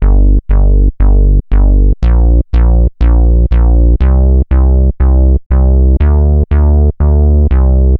Session 08 - Bass 04.wav